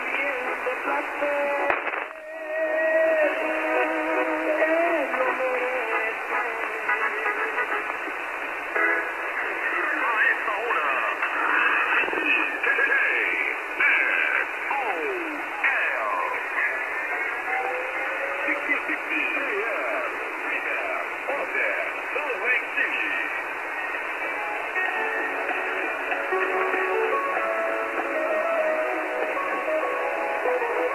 ID: identification announcement
KXOL Brigham City,UT USA　->00'10":ID:KXOL(slow man)->16-60 AM->